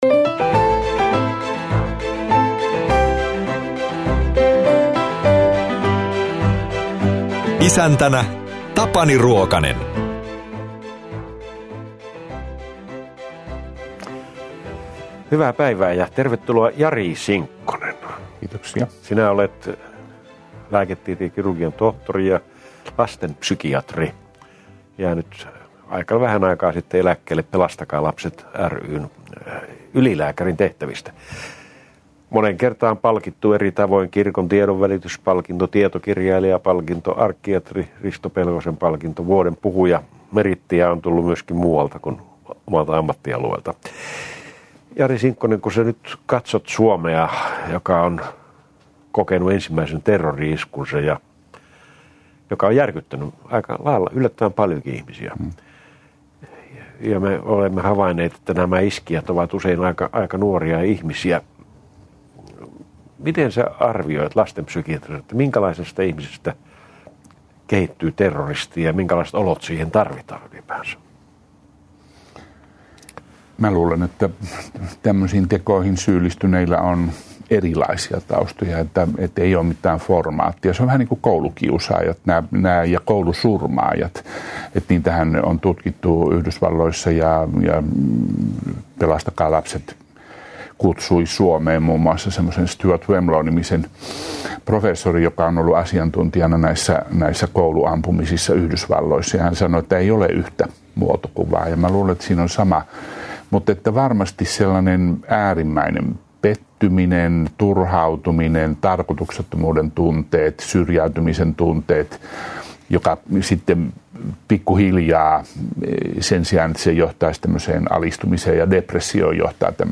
haastattelu